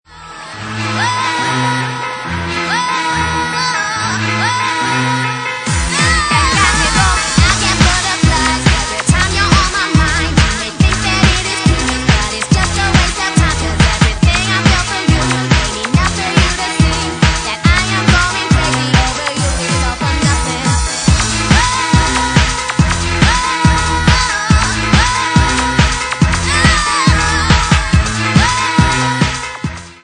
Bassline House at 141 bpm